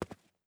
ES_Footsteps Concrete 1.wav